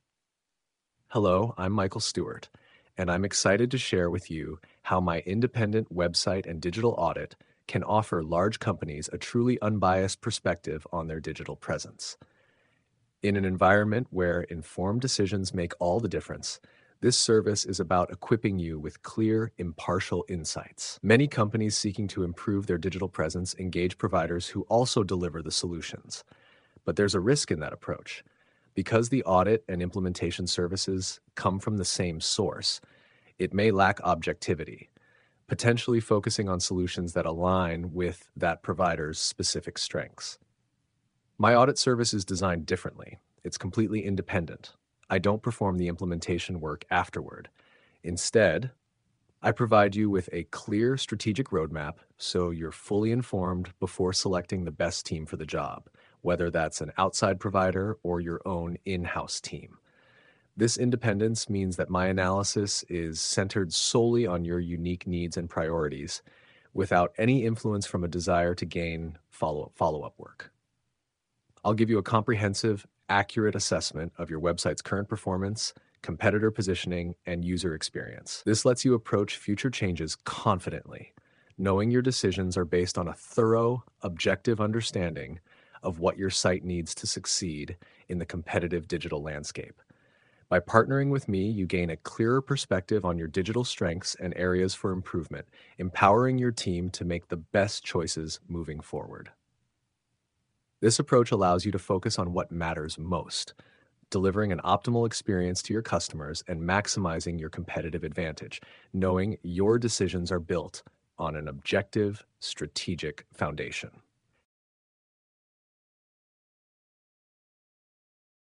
(AI Voice)